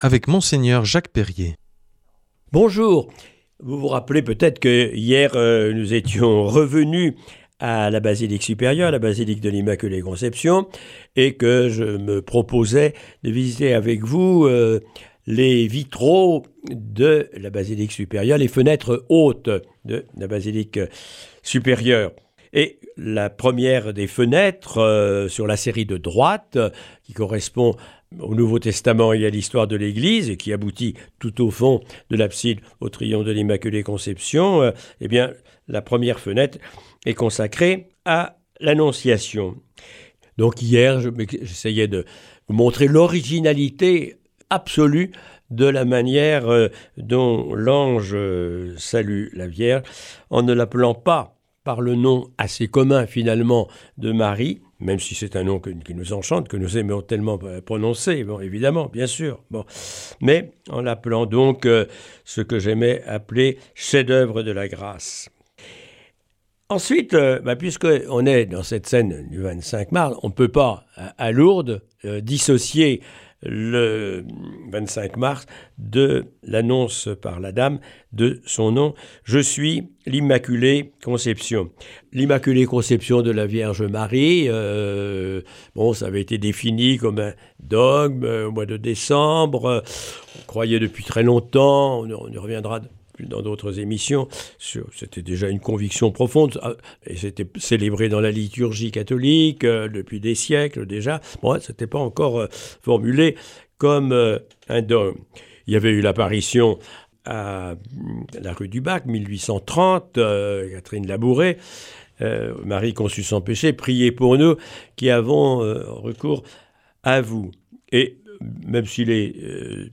Enseignement Marial du 19 nov.
Cette semaine, Mgr Jacques Perrier nous propose de poursuivre la découverte des vitraux de la bisilique de l’Immaculée Conception à Lourdes.